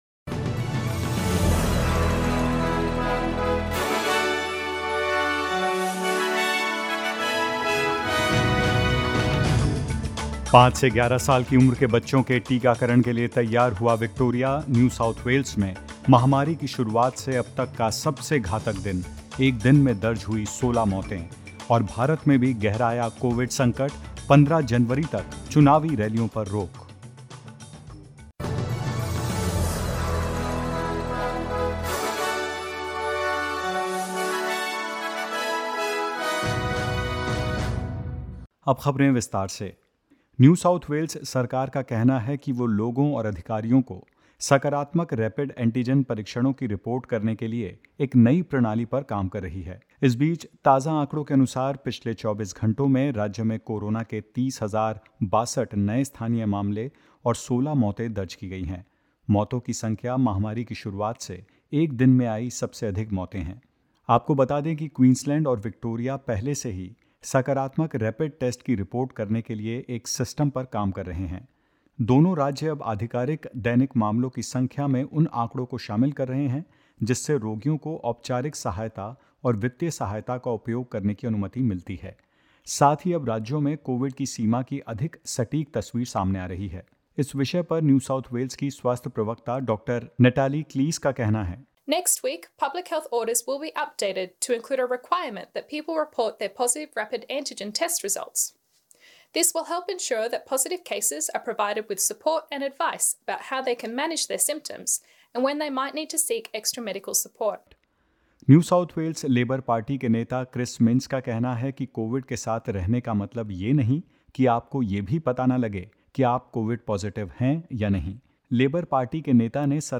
In this latest SBS Hindi news bulletin: New South Wales says it's working on a system for people to report positive rapid antigen tests to authorities; England fights hard to avoid Australia's clean sweep of the Ashes and more.